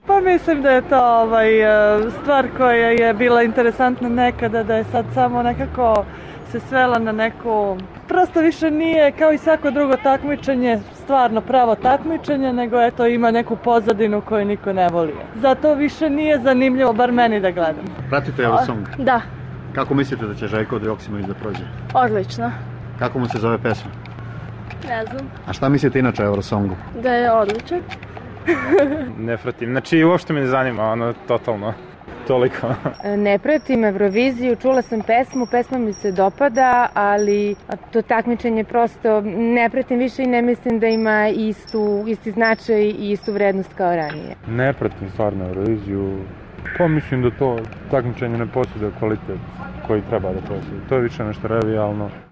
Beograđani o Evroviziji